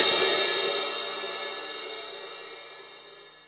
paiste hi hat6 open.wav